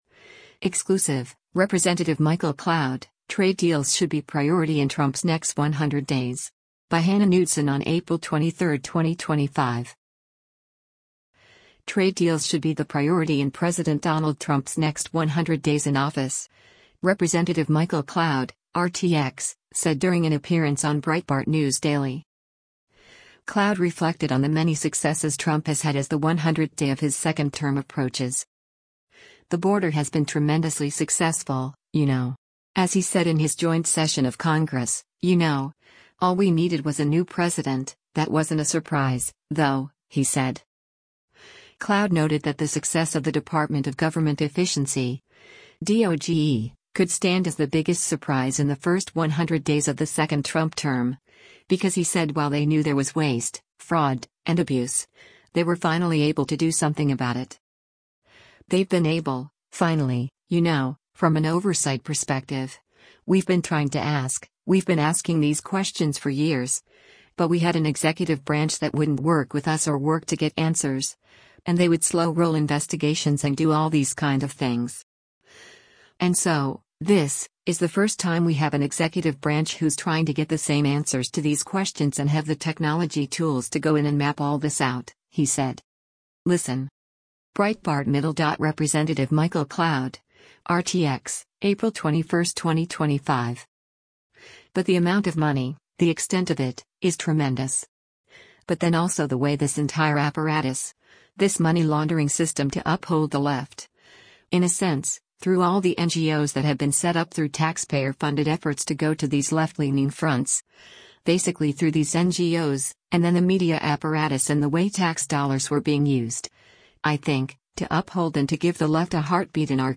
Trade deals should be the priority in President Donald Trump’s next 100 days in office, Rep. Michael Cloud (R-TX) said during an appearance on Breitbart News Daily.